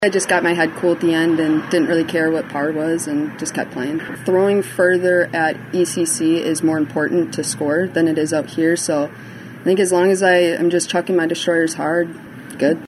The 3 leaders talked with the Disc Golf Network after the second round.